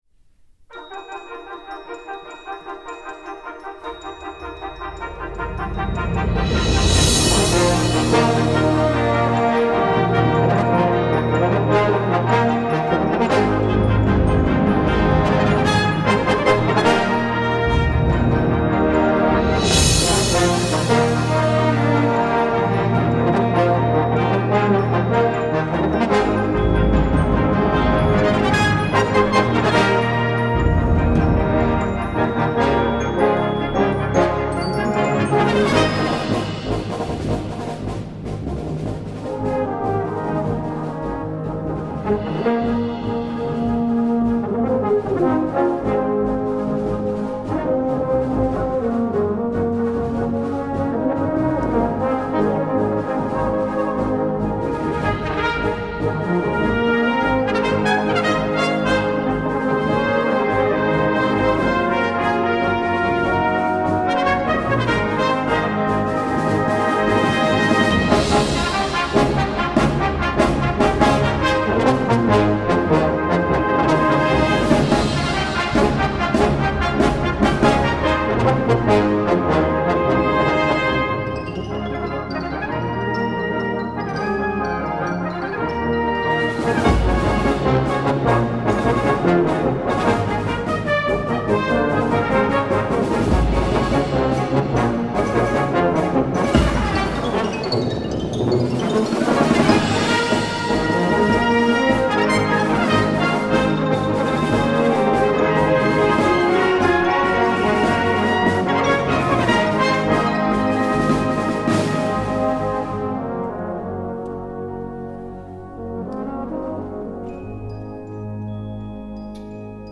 2. Brass Band
Full Band
without solo instrument